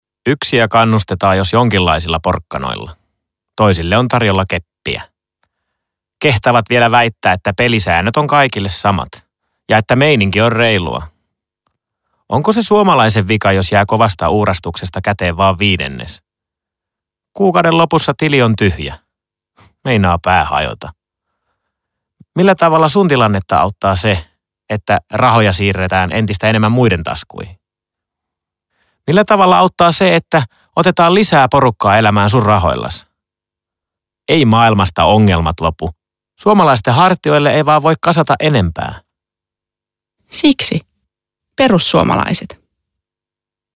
Radiospotit